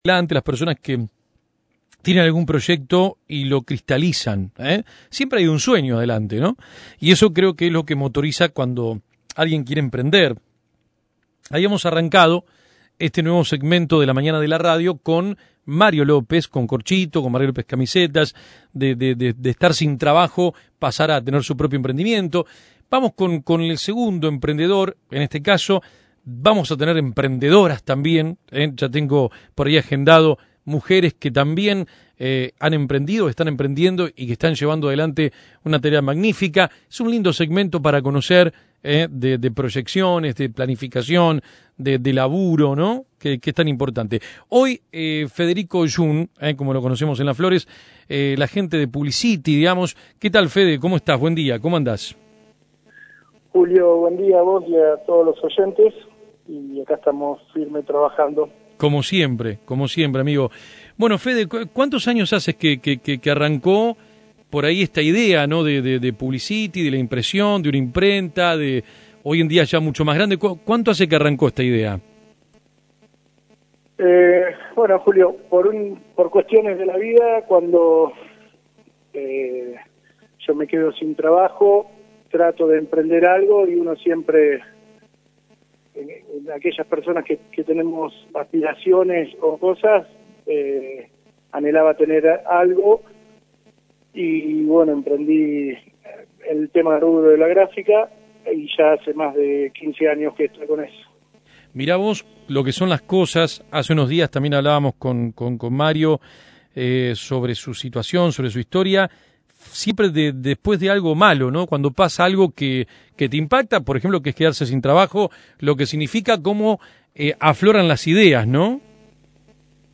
El segmento de la mañana de AM 1210, En Contacto, donde destacamos el trabajo local, empresas, pequeños emprendimientos e historias de gente que más allá de los obstáculos en la vida, hicieron posible su sueño o proyecto.